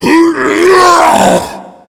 controller_die_1.ogg